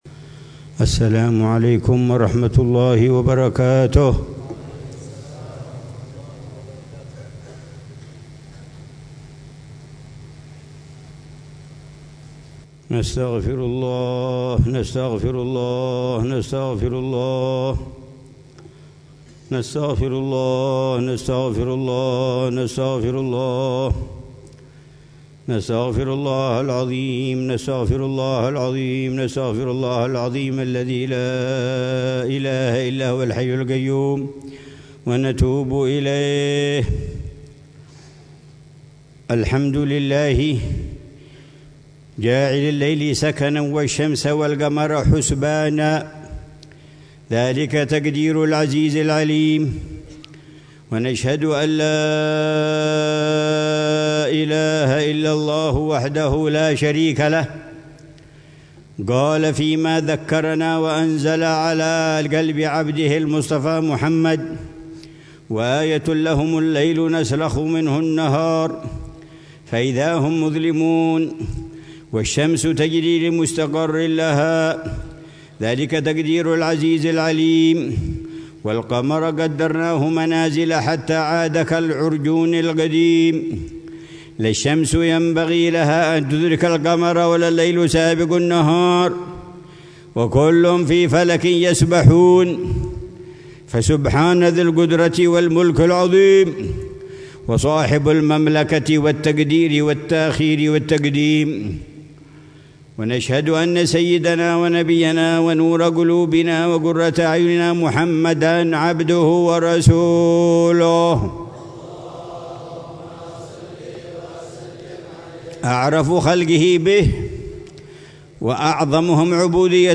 خطبة الخسوف في دار المصطفى بتريم - ربيع الأول 1447
خطبة الخسوف للحبيب العلامة عمر بن محمد بن حفيظ، في دار المصطفى بتريم للدراسات الإسلامية، ليلة الإثنين 16 ربيع الأول 1447هـ